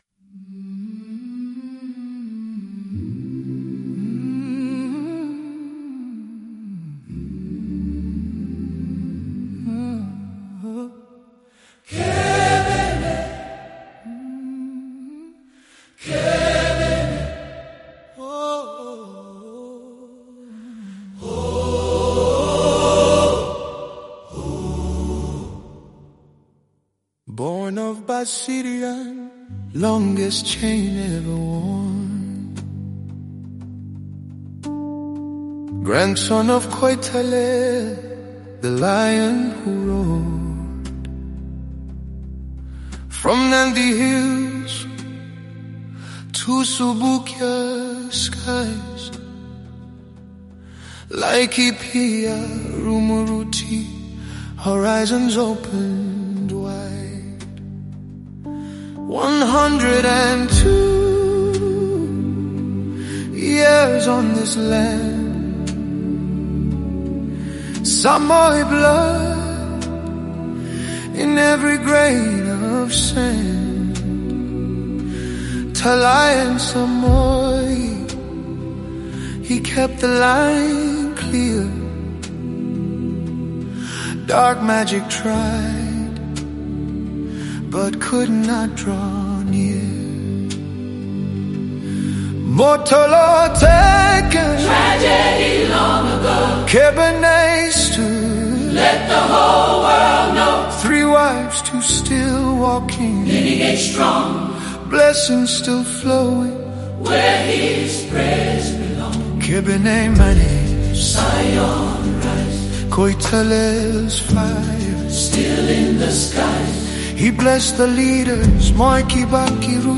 AI-composed music honoring Koitaleel Samoei and the larger House of Turgat
These songs breathe life into ancient Kalenjin rhythms, prophetic chants, and warrior spirit — composed with AI as a collaborator, honoring those who held the line.
Prophetic tribute · vocals & traditional instruments